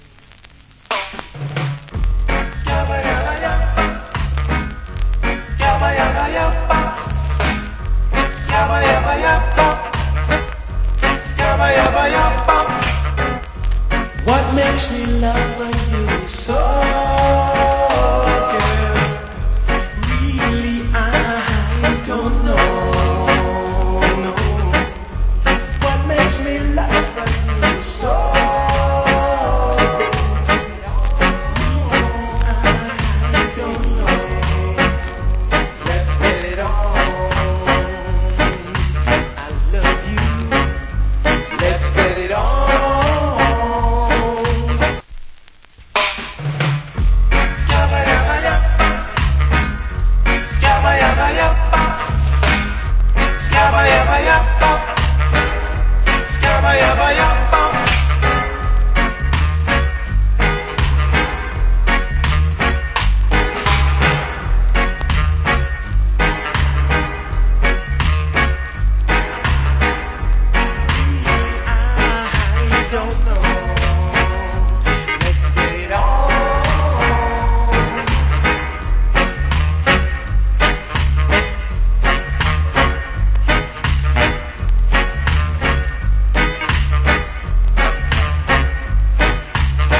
Nice Rock Steady.